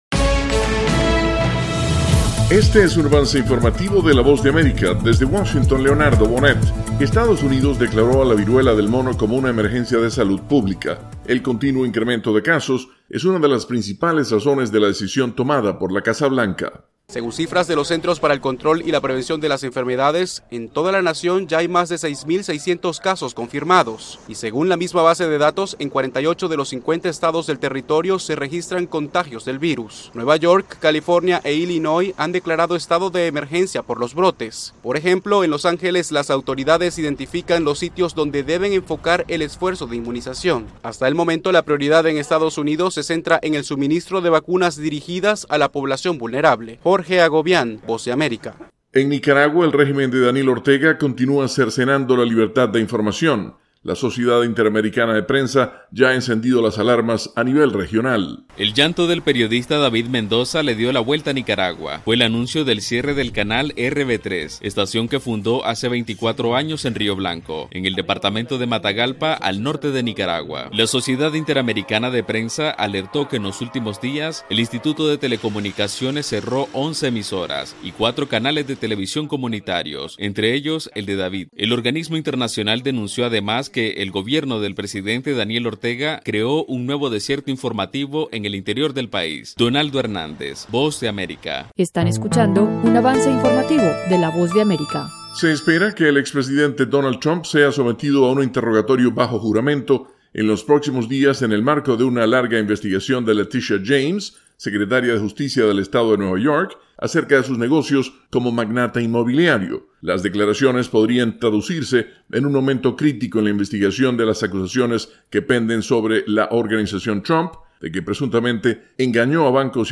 Avance Informativo - 7:00 PM
El siguiente es un avance informativo presentado por la Voz de América, desde Washington